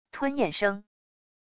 吞咽声wav音频